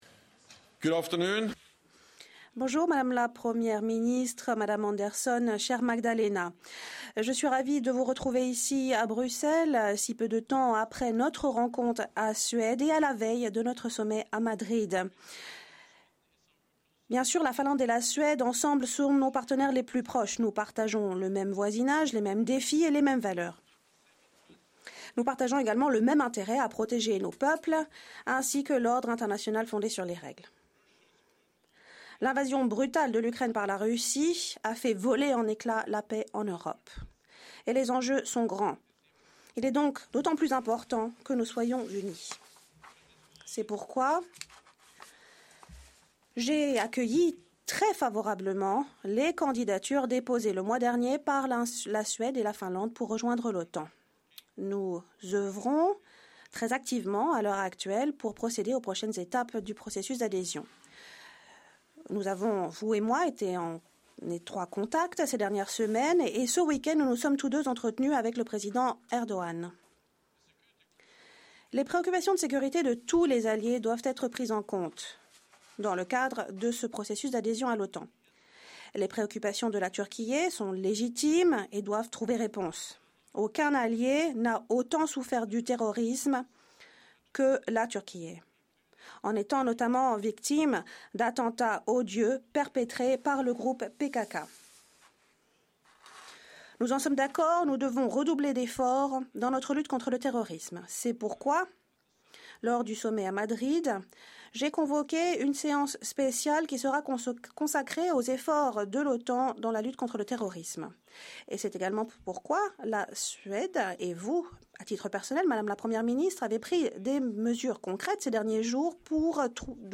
Statements
by the NATO Secretary General Jens Stoltenberg and the Prime Minister of Sweden, Magdalena Andersson